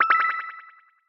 Win_Eliminate_Sound.mp3